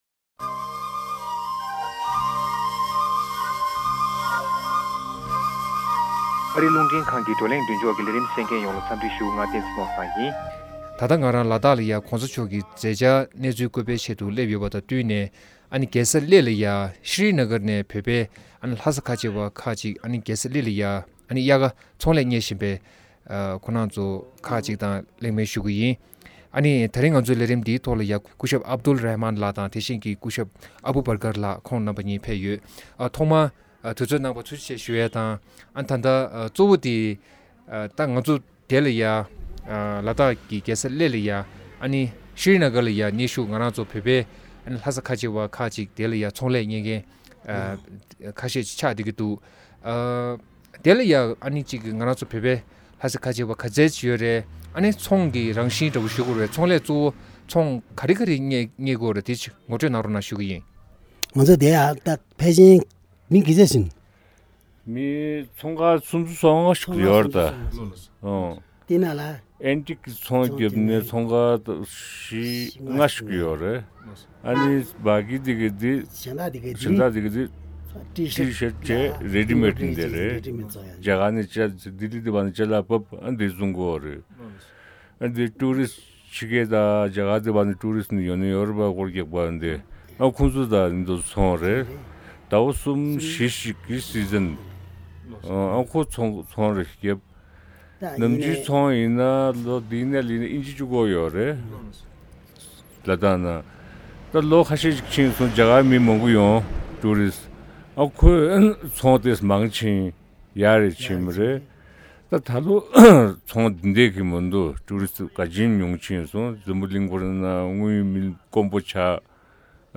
གླེང་མོལ་ཞུས་་པ་གསན་རོགས།